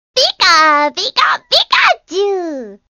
Звуки Пикачу
Голос покемона Пикачу